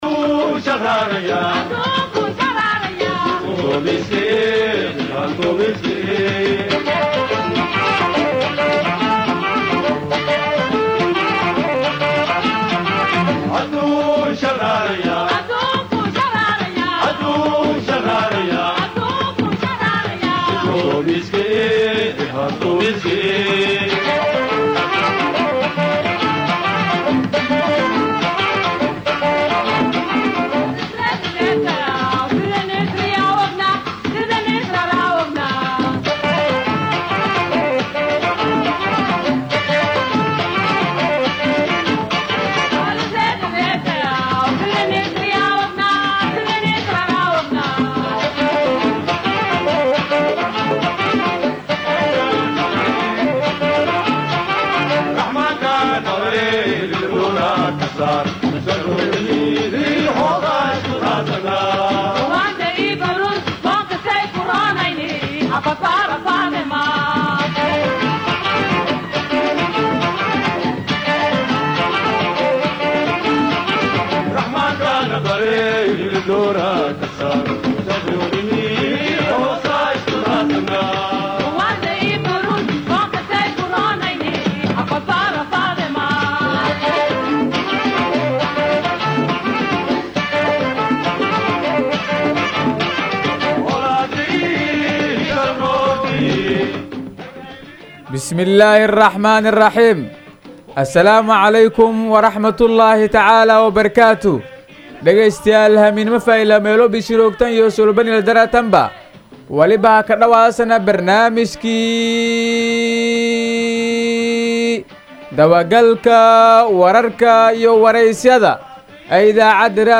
BAYDHABO–BMC:–Dhageystayaasha Radio Baidoa ee ku xiran Website-ka Idaacada Waxaan halkaan ugu soo gudbineynaa Barnaamijka Dabagalka Wararka iyo Wareysiyada ee ka baxay Radio Baidoa.